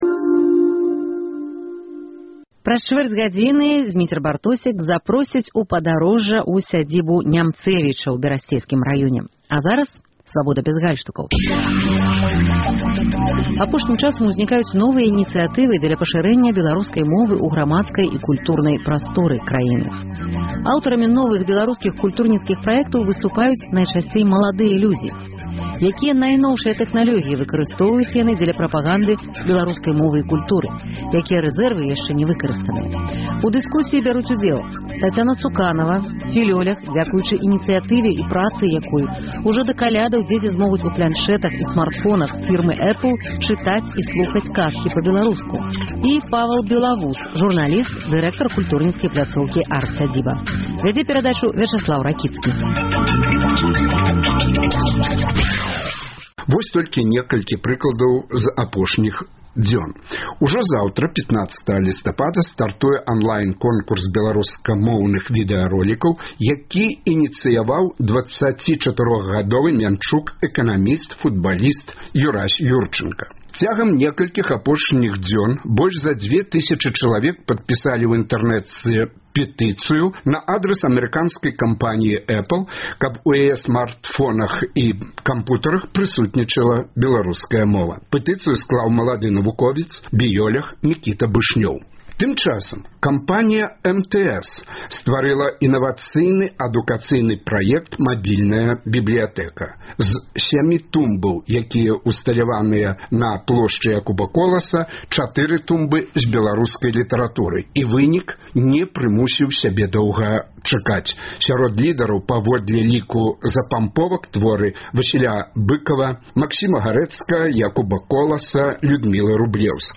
Пра гэта дыскутуюць філёляг